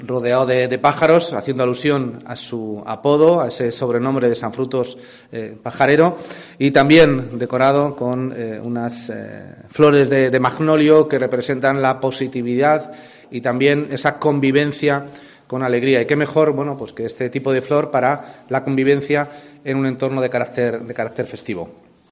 Juan Carlos Monroy, concejal de Cultura del Ayuntamiento de Segovia